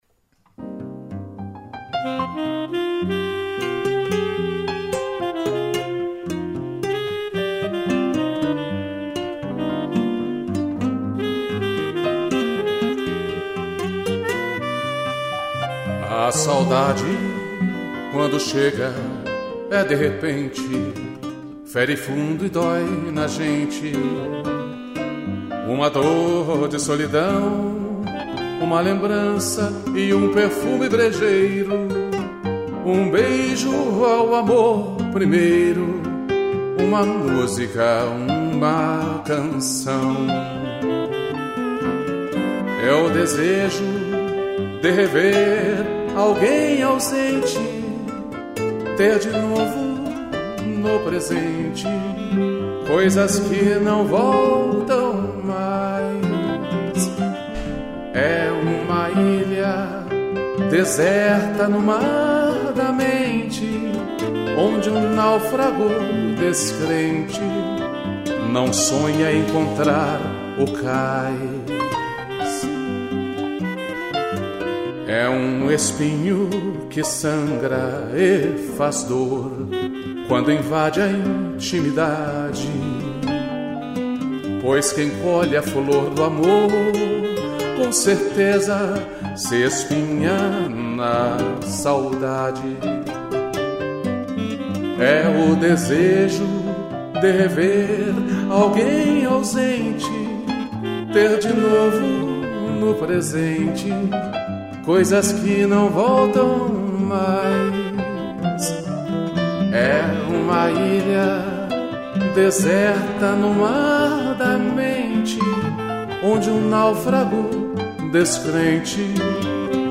voz e violão
piano e sax